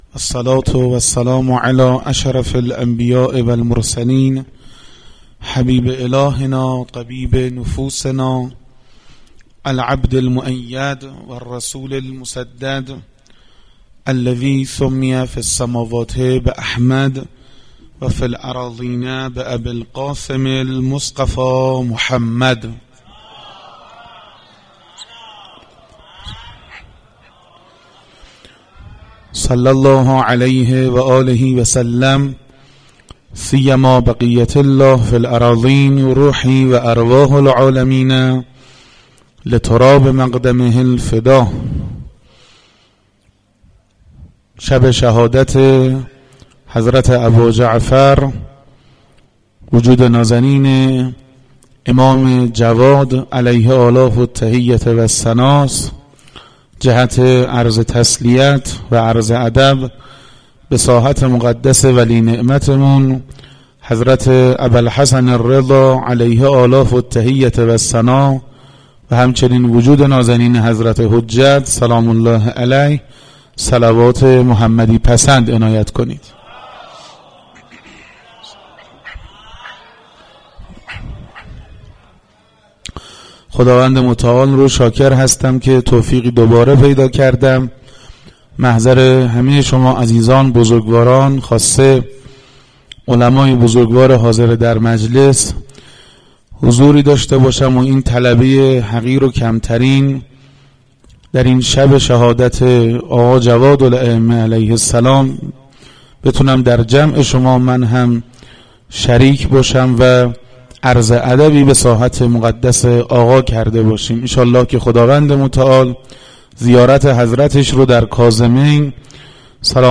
سخنرانی
مراسم شهادت امم جواد (ع)